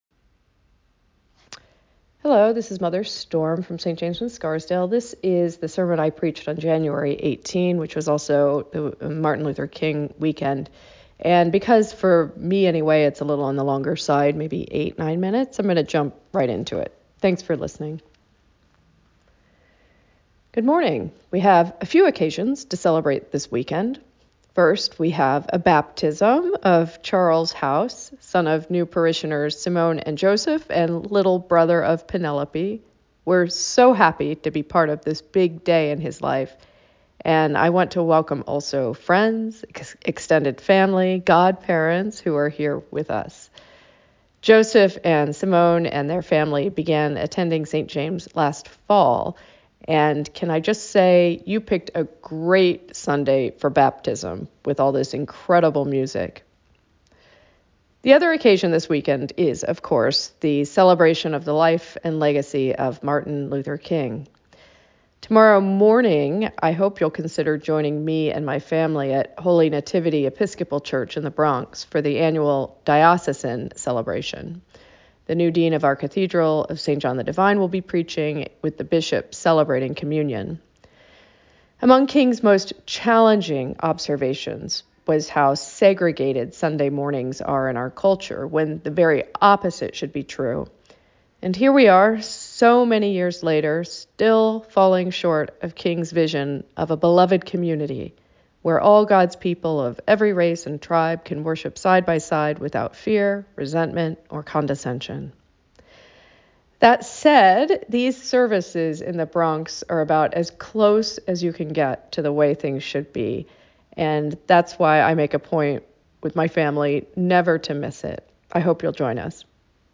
Sermon Podcast | Church of St. James the Less